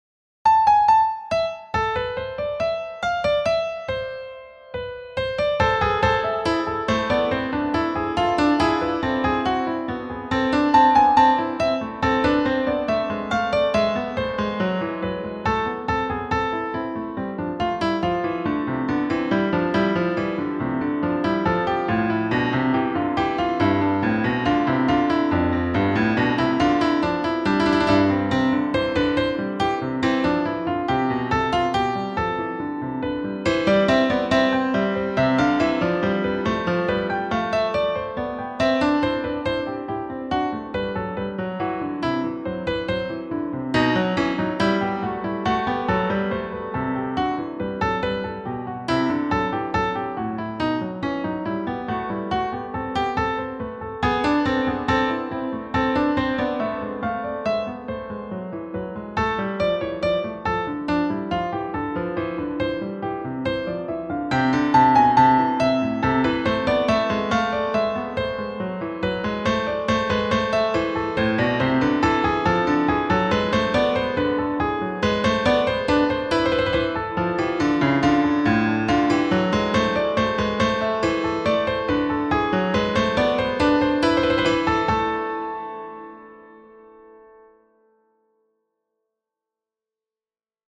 Music
I composed this Bach-esque Invention in 1984.
However, GarageBand has enabled me to perform it in a modern way.